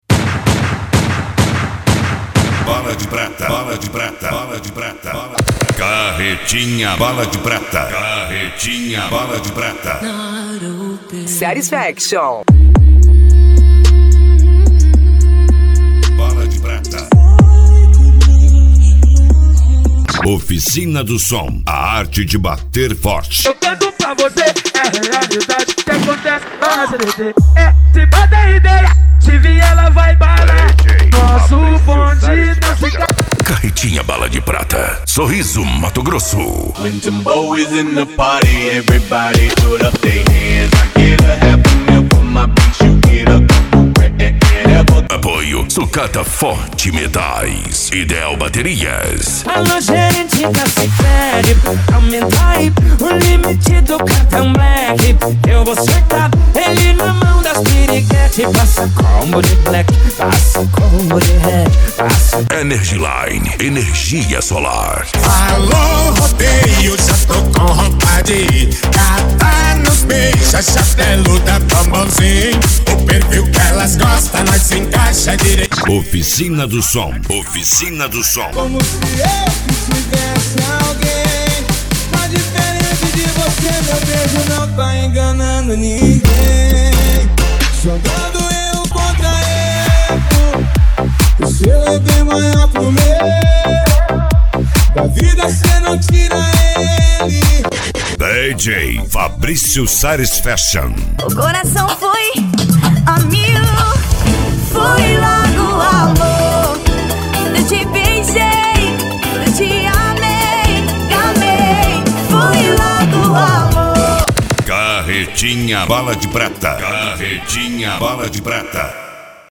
Deep House
Remix
SERTANEJO
Sertanejo Universitario